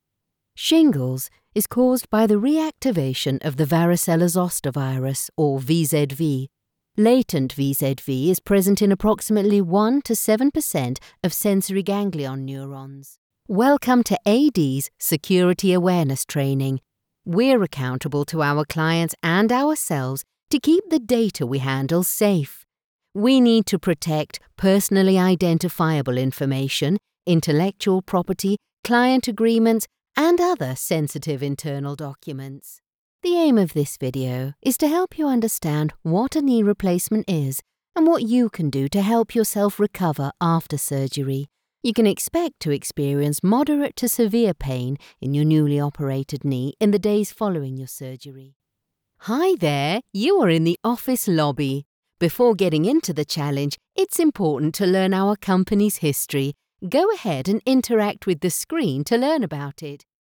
E-Learning
Mein neutraler englischer Akzent kommt auf internationalen Märkten gut an.
Meine Stimme ist natürlich und freundlich, aber auch vollmundig und bestimmend.
Audio Technica AT2020 Mikrofon